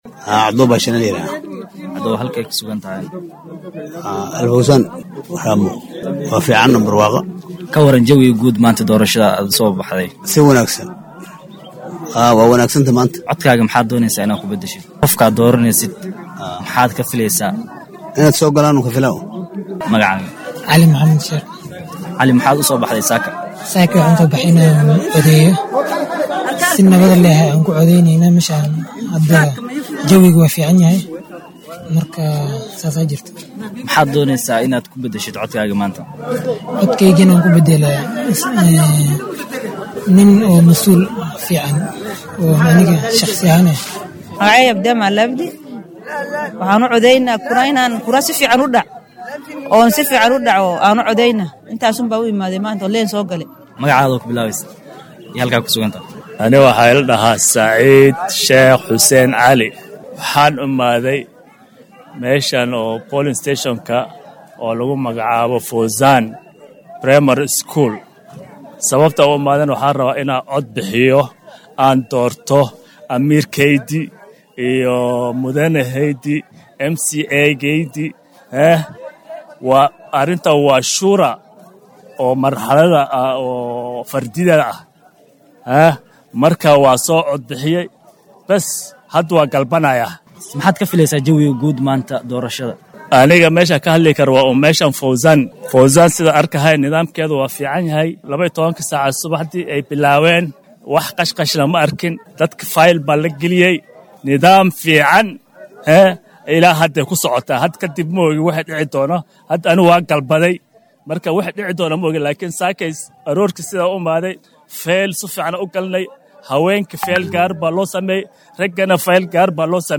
Meelaha ay dadweynaha Mandera codkooda ka dhiibanayaan waxaa ka mid ah dugsiga hoose dhexe ee Al-Fowzan oo ku yaalla Rhamu.